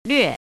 怎么读
lüè
略 [lüè]
lve4.mp3